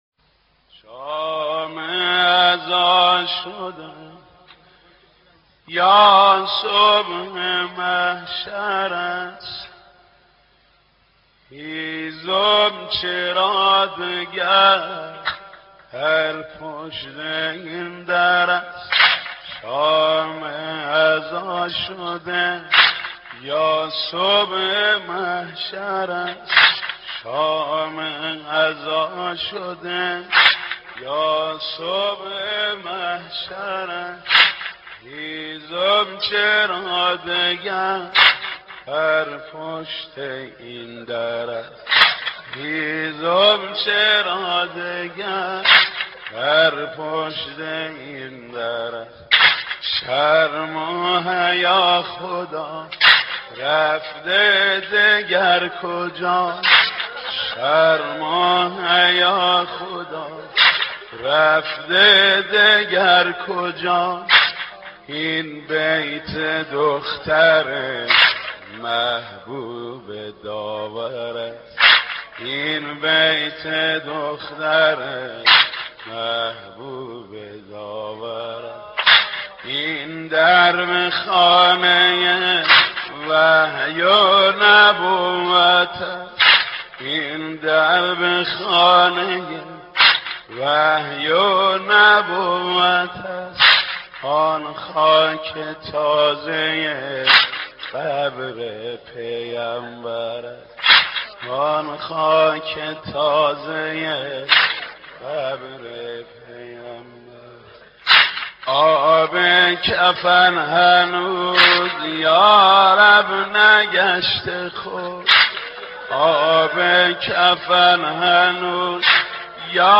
مراسم نوحه‌خوانی برای سوگ شهادت دخت پیامبر حضرت زهرا (س)